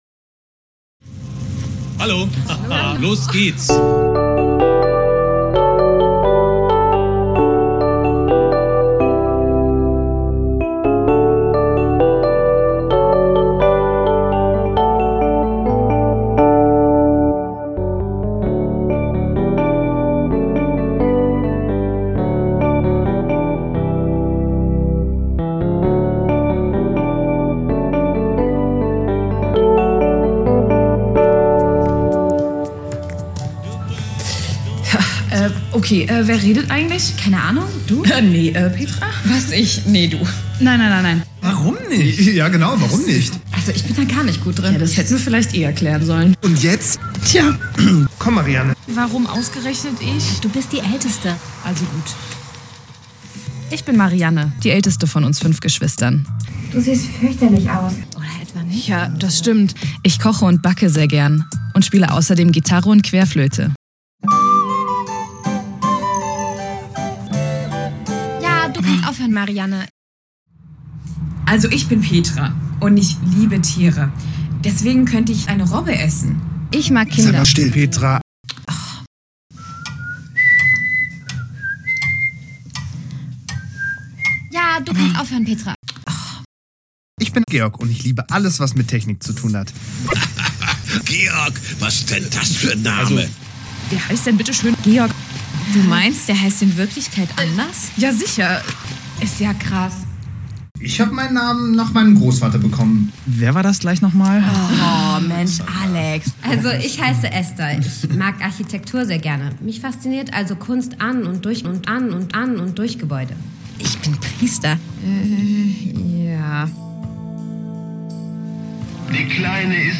Fan-Hörspiele
5-Geschwister-Quatsch-1.mp3